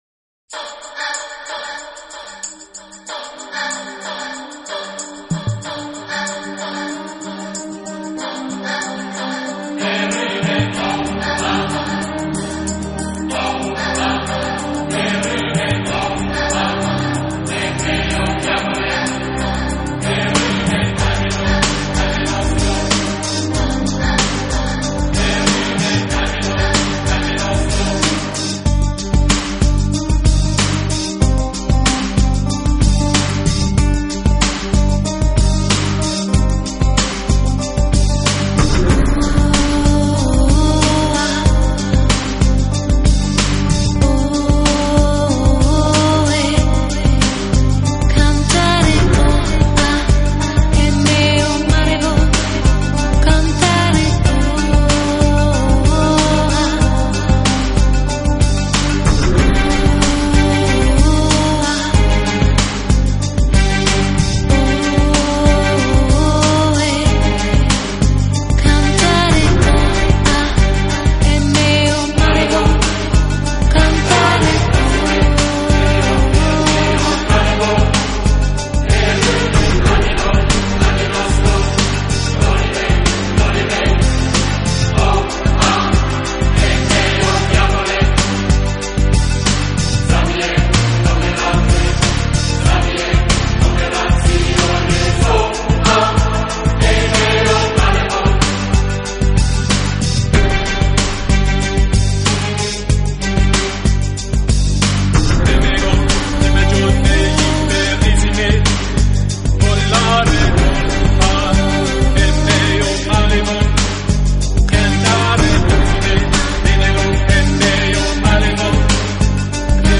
都是圣歌与现代器乐的融合，甚至乍听起来，还颇为相似。
唱的戏分更重了。
强，很易上口，感觉上更流行化一些吧。